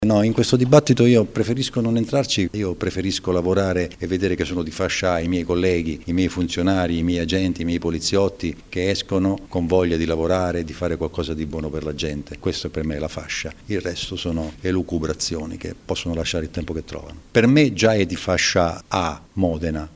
Pennella è intervenuto anche sulla questione dell’elevazione di fascia della questura modenese: